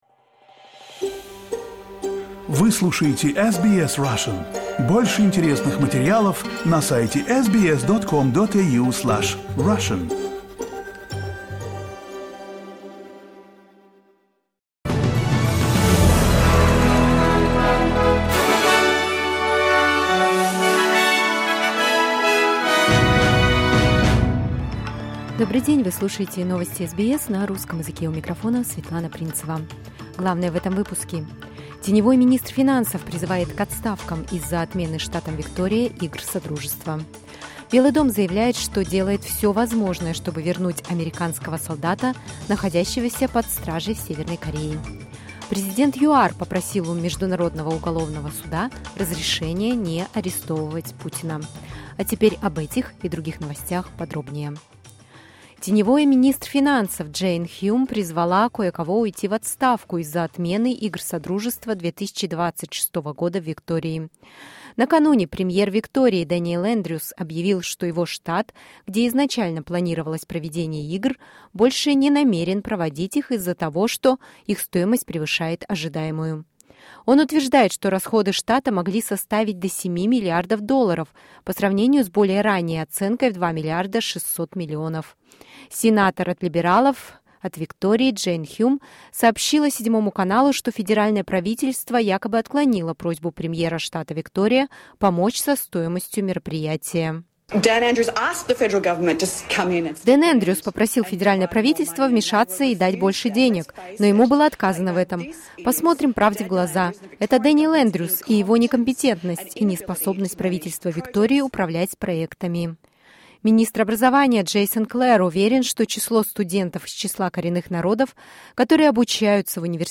SBS news in Russian — 19.07.2023